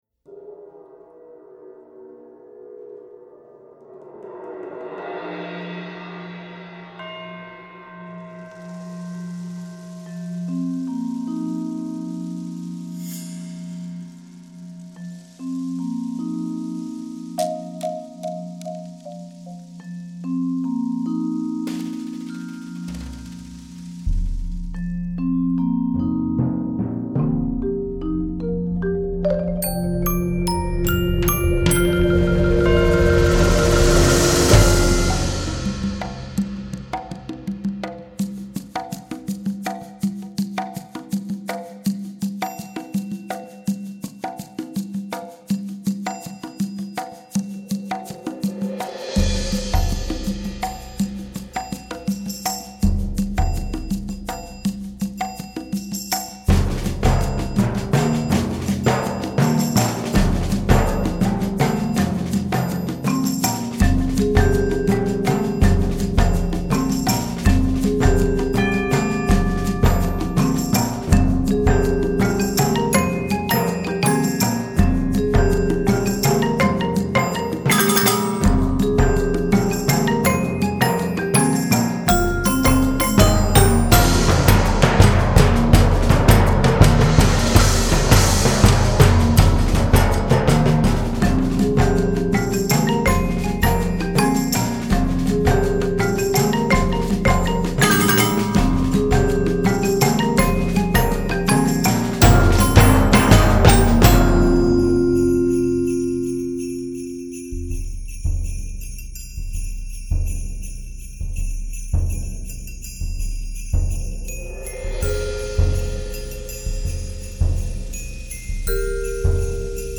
Voicing: 14 Percussion